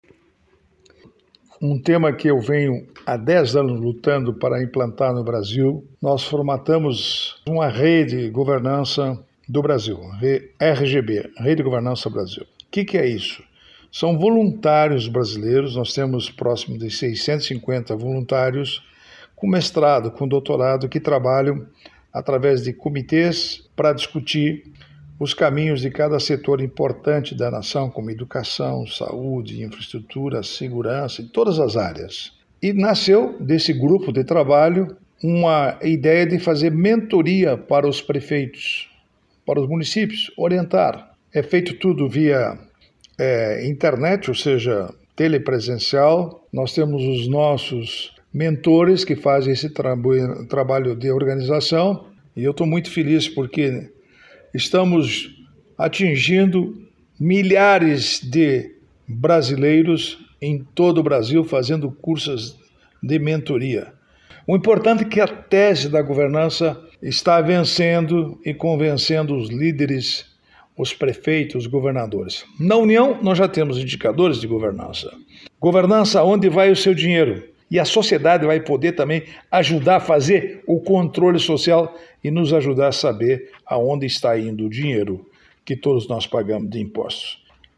É comentário do Ministro do TCU, Augusto Nardes.